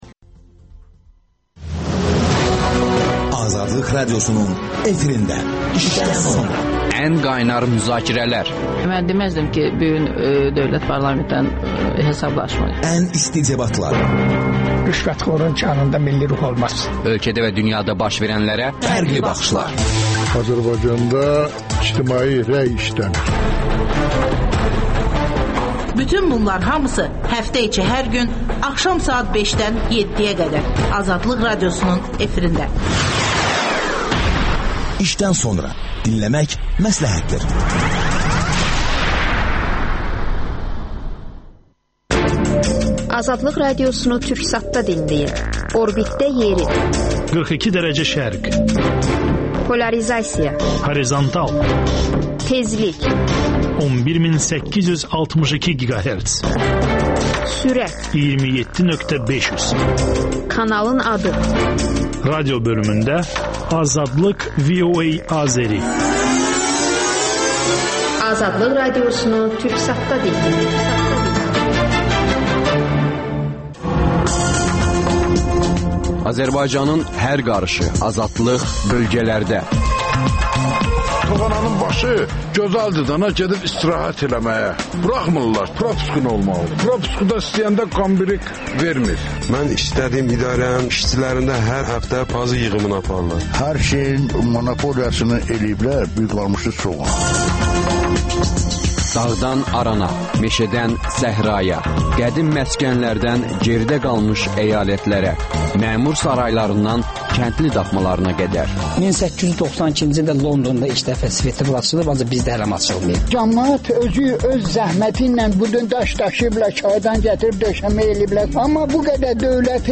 Sabiq deputat, iqtisadçı Nazim Məmmədovla söhbət.